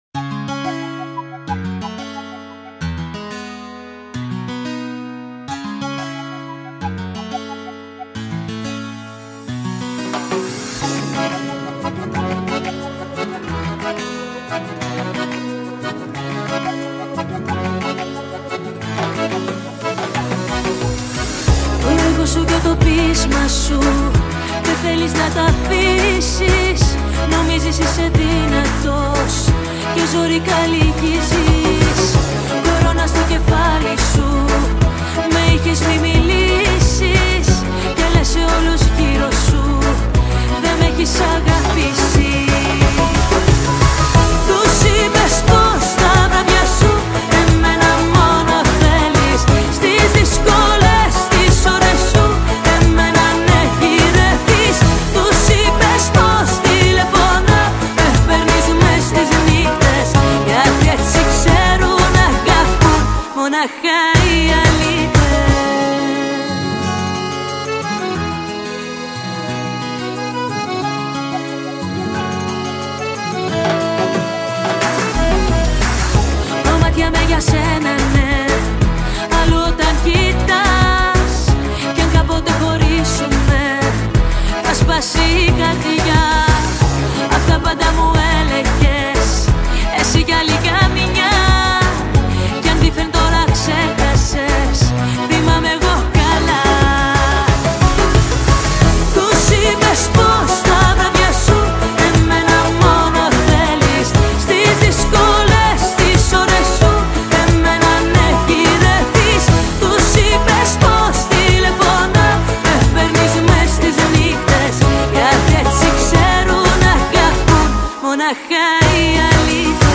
Жанр: ΠΟΠ και ΛΑΪΚΆ